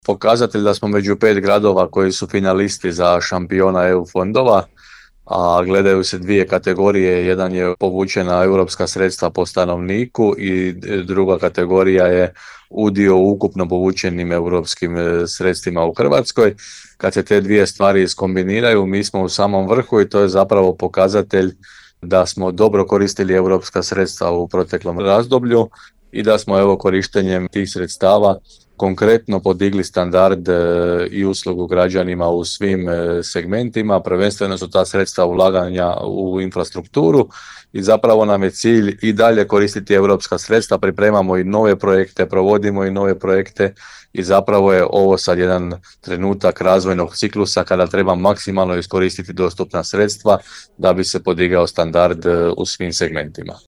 – rekao je gradonačelnik Hrvoje Janči.